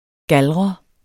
Udtale [ ˈgalʁʌ ]